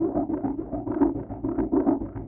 Index of /musicradar/rhythmic-inspiration-samples/105bpm